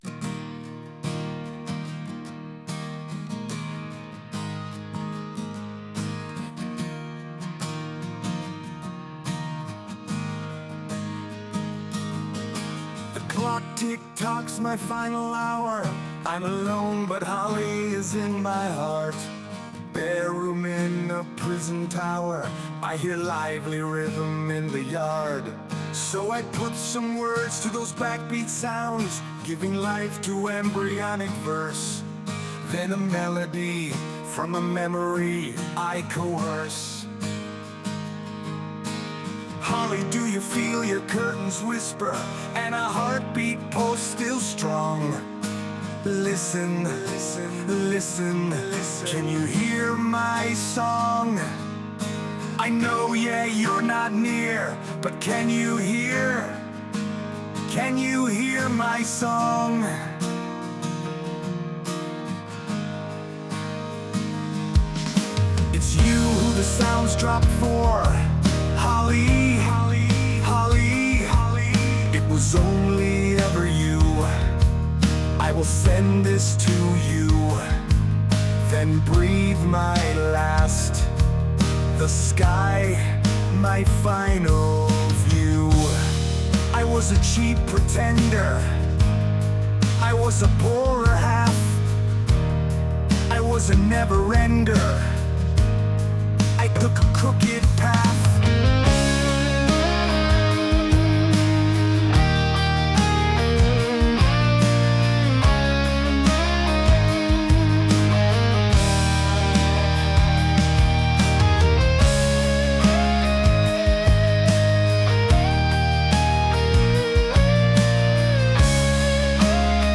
I’ve also had a crack at a couple of ai versions…